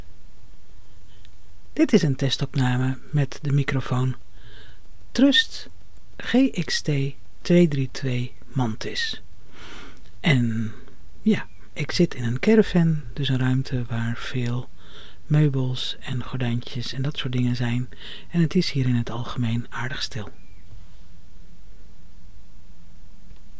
Zelf heb ik al een poos de microfoon: Trust GXT 232 MANTIS.
testgeluid-mircrofoon-trustgxt232MANTIS-meditaties.mp3